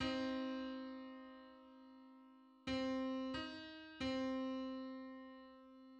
Just: 77/64 = 320.14 cents.
Public domain Public domain false false This media depicts a musical interval outside of a specific musical context.
Seventy-seventh_harmonic_on_C.mid.mp3